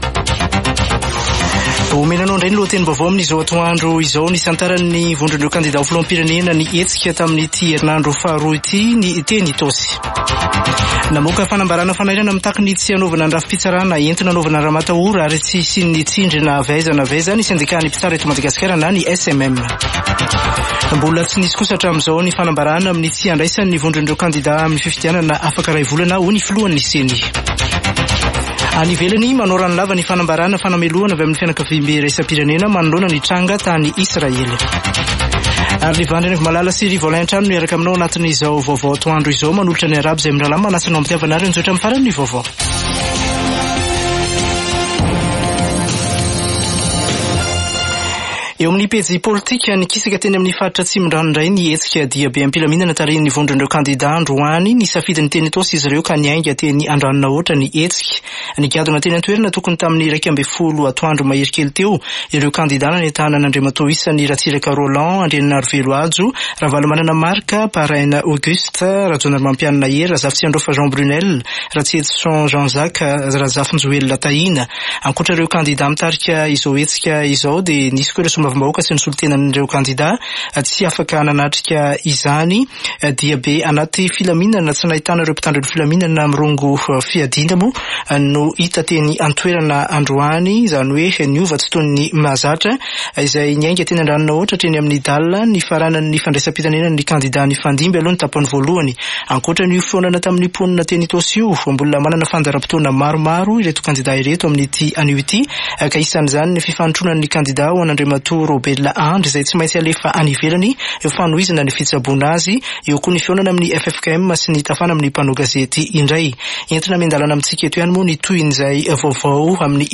[Vaovao antoandro] Alatsinainy